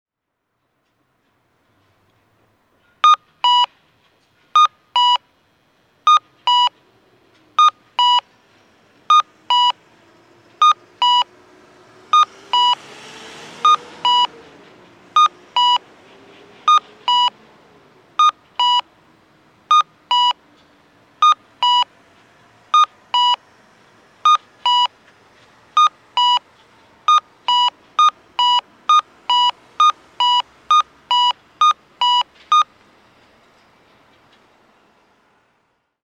中津留1丁目(大分県大分市)の音響信号を紹介しています。